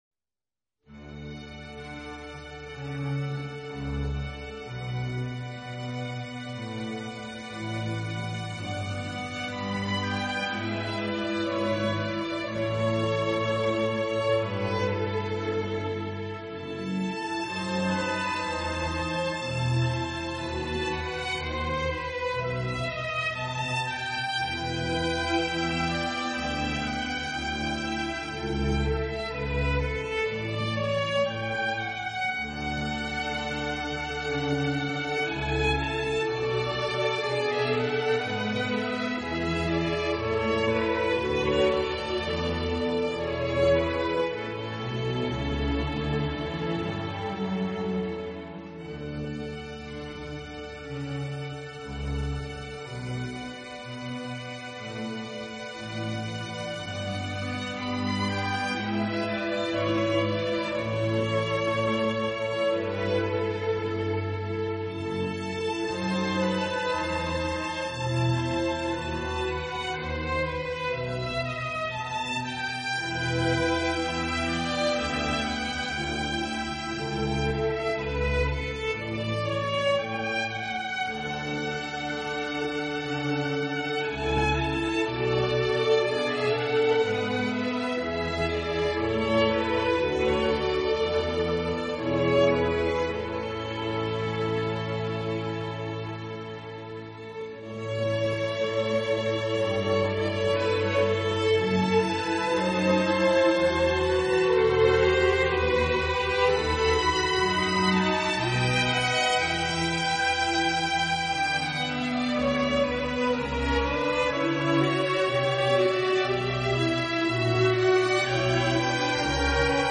音乐类型：古典 小提琴